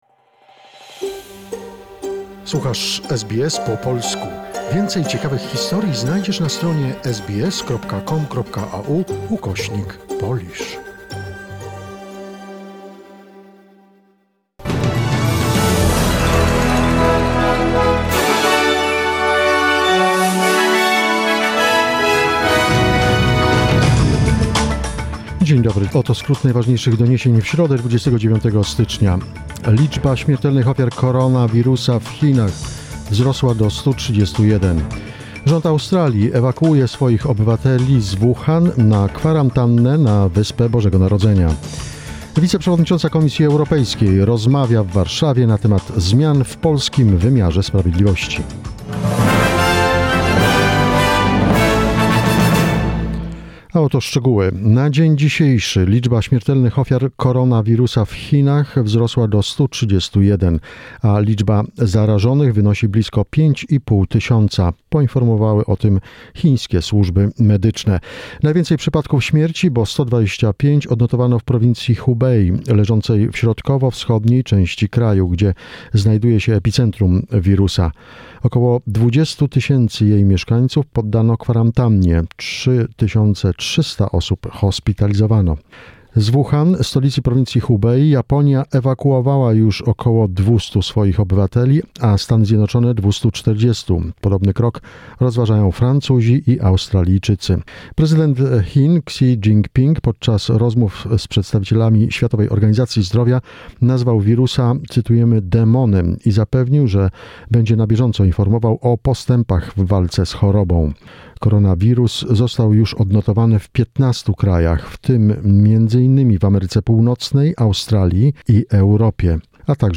SBS News 29 January 2020